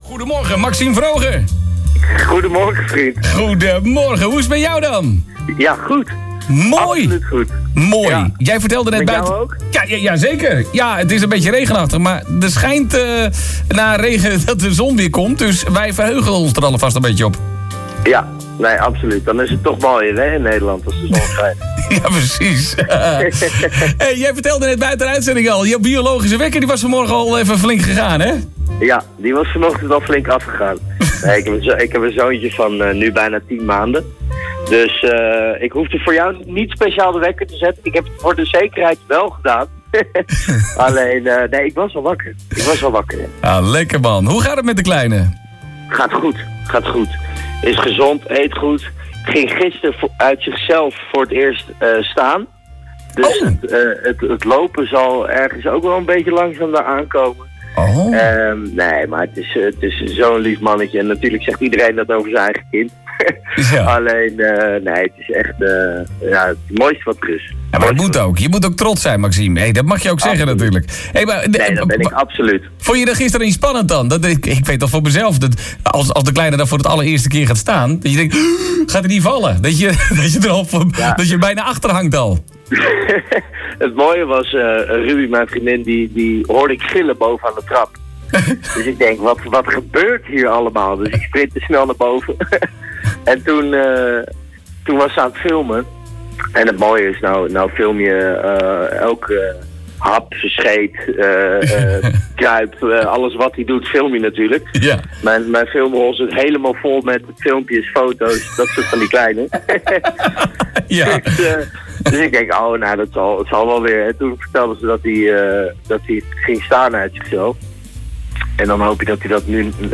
Het gesprek was warm, persoonlijk en muzikaal.